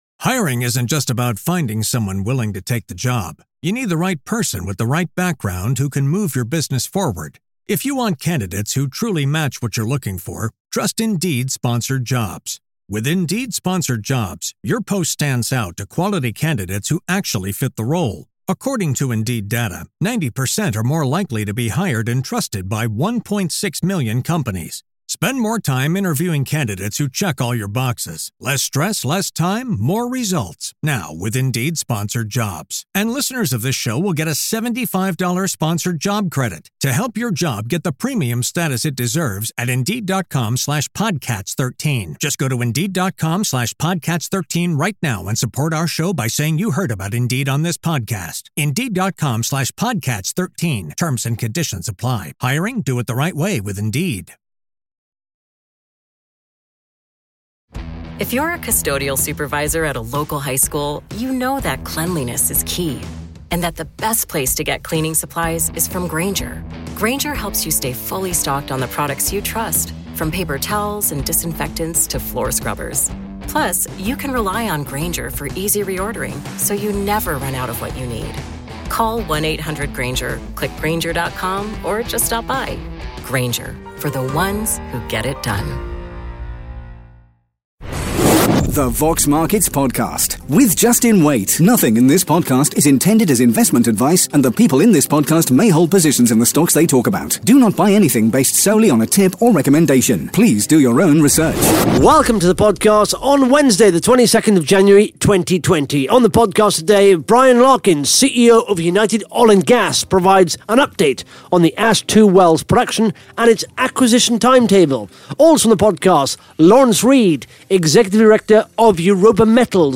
(Interview starts at 11 minutes 30 seconds) Vox Markets is revolutionising the way companies engage with shareholders and the stock market at large.